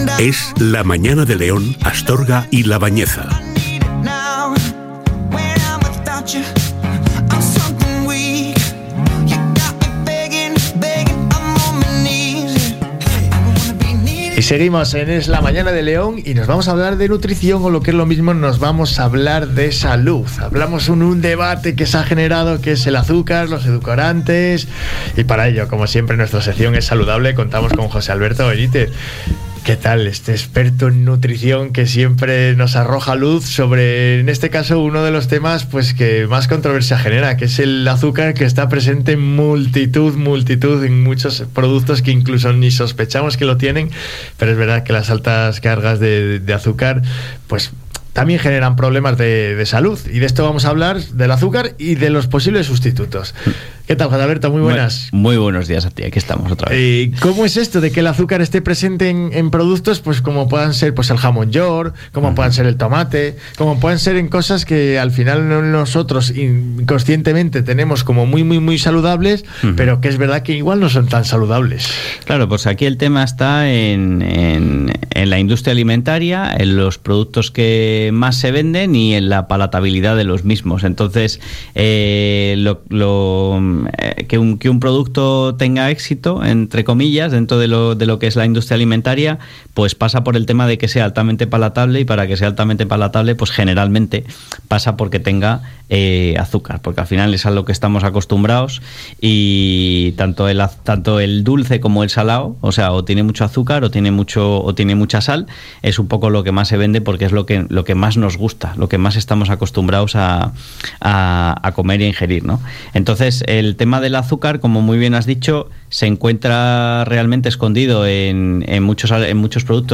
Hoy os traigo el tricentésimo sexagésimo tercerprograma de la sección que comenzamos en la radio local hace un tiempo y que hemos denominado Es Saludable, en el programa Es la Mañana de León, Astorga y La Bañeza en EsRadio.